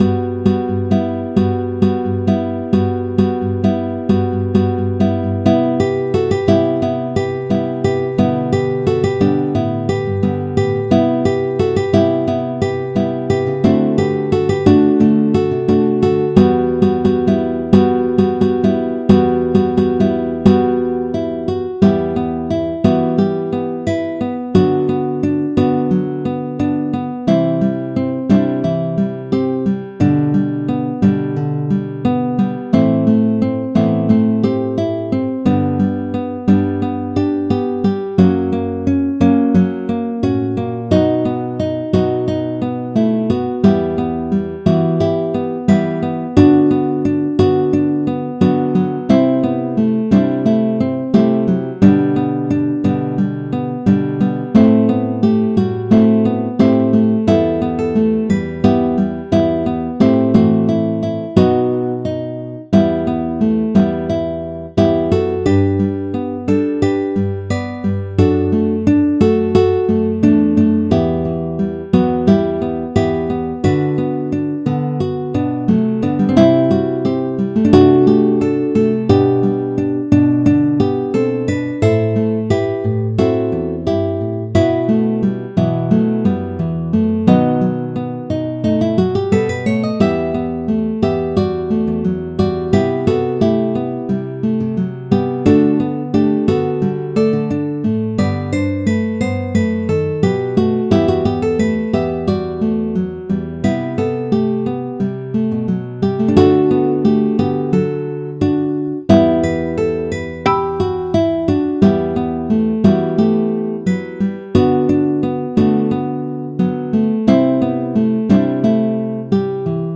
「MP3]はMIDIデータからサウンドフォントを利用して変換した音楽再生用データです。